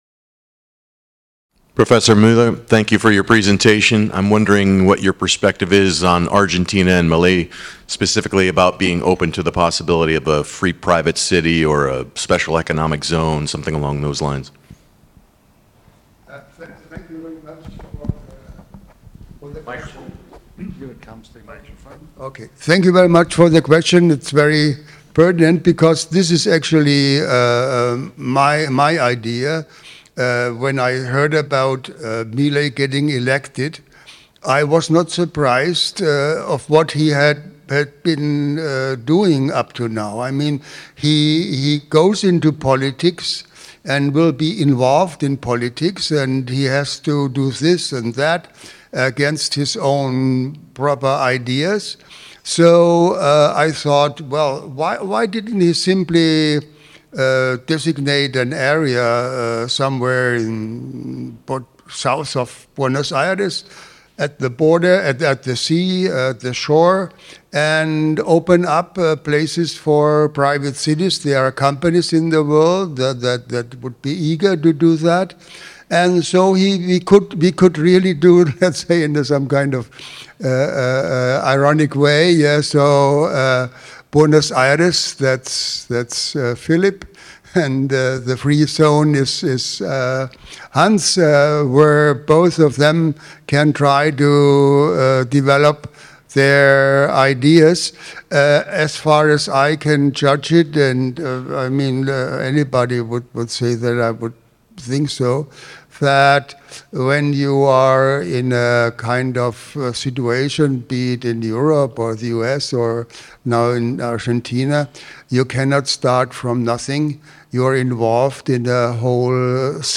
This talk is from the 18th annual 2024 Annual Meeting of the PFS (Sept. 19–24, 2024, Bodrum, Turkey).